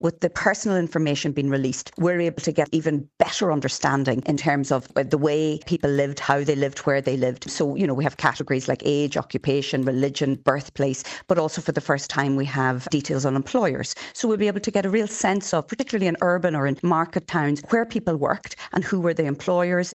Orlaith McBride, Director of the National Archives of Ireland, says there’s a wealth of information available: